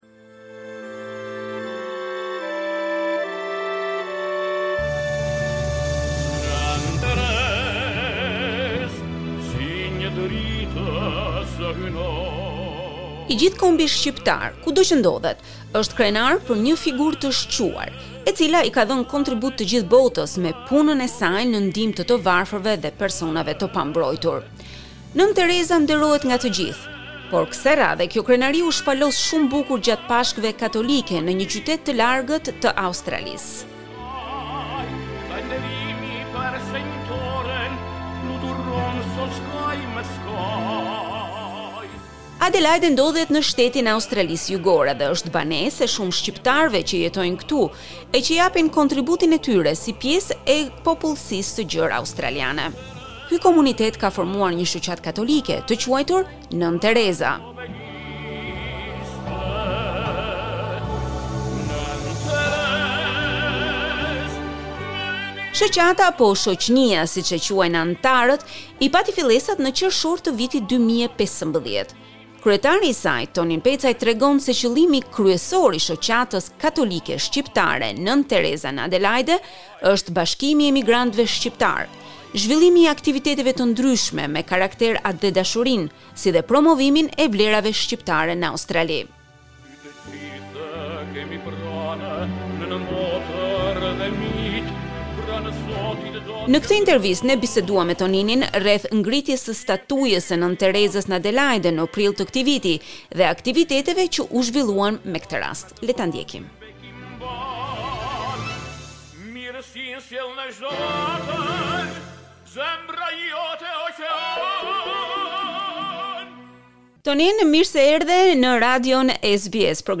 Ne kete interviste